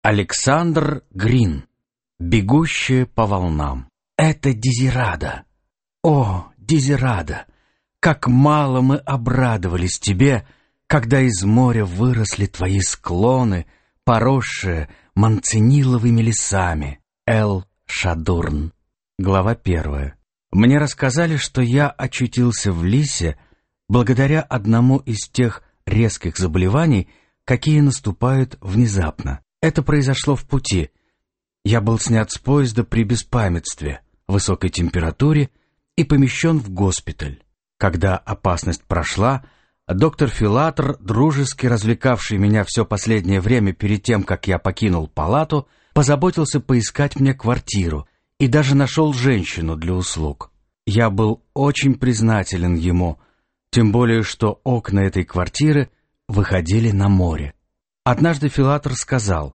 Аудиокнига Бегущая по волнам | Библиотека аудиокниг